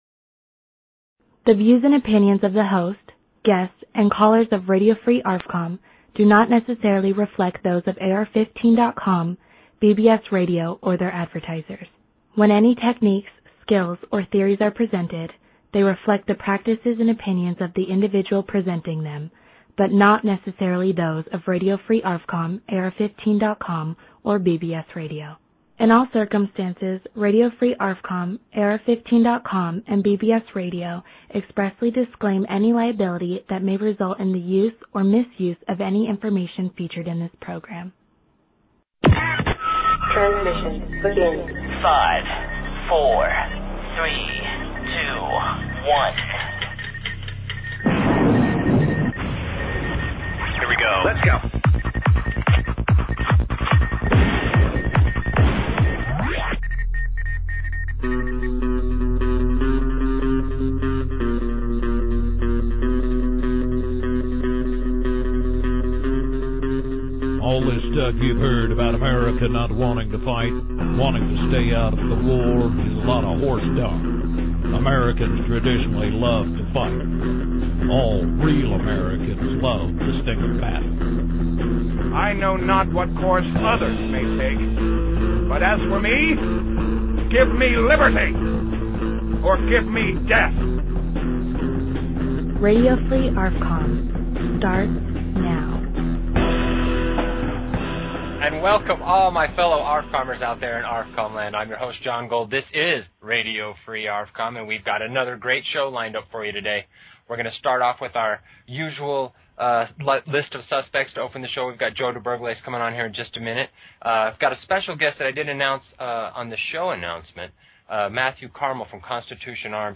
Talk Show Episode, Audio Podcast, Radio_Free_ARFCOM and Courtesy of BBS Radio on , show guests , about , categorized as